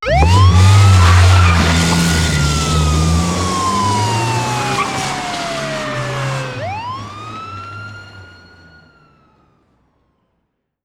Salida rápida de un coche de policía
policía
coche
sirena
Sonidos: Transportes
Sonidos: Ciudad